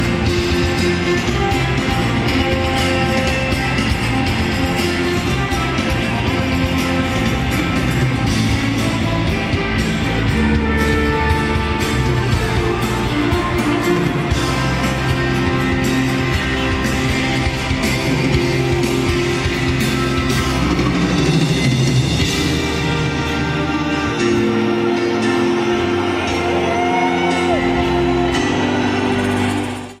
Format/Rating/Source: CD - D- - Audience
Comments: Poor audience recording.
Sound Samples (Compression Added):